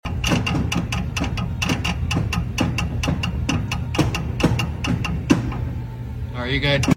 2024 Chevy Silverado ZR2 rear sound effects free download